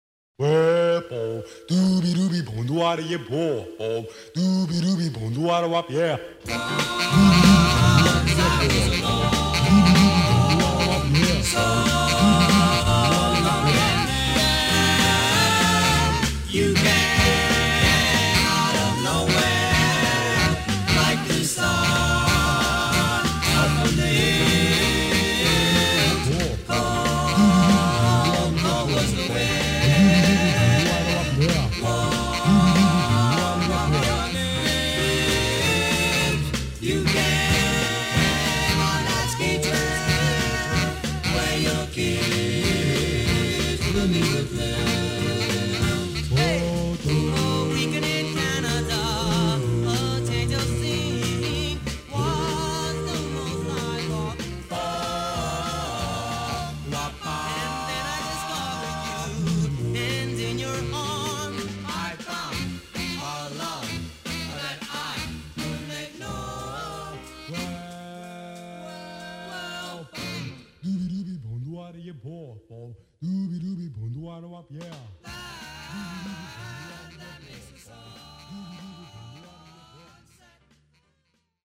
Doo-wop
(Doo-wop)